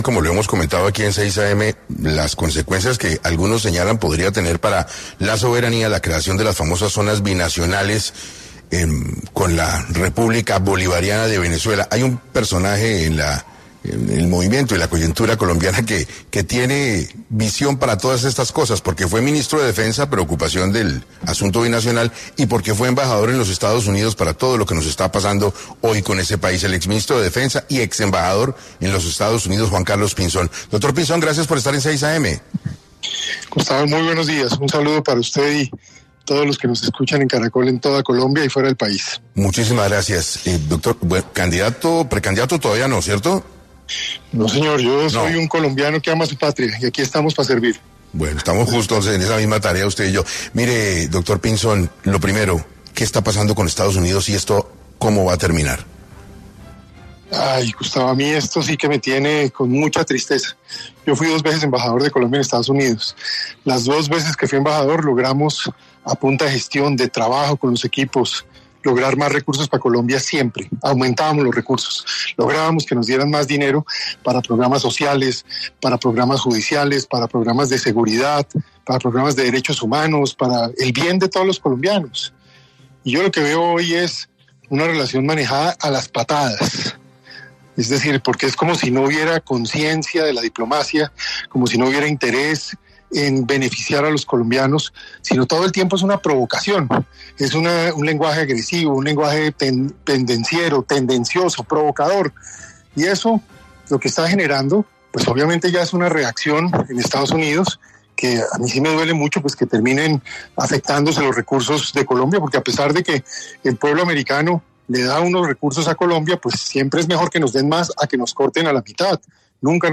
Juan Carlos Pinzón, exministro de Defensa, estuvo en 6AM para hablar sobre las relaciones bilaterales con Venezuela.
En este orden de ideas, el exministro de Defensa y exembajador en los Estados Unidos, Juan Carlos Pinzón, pasó por los micrófonos de 6AM, para abordar este tema.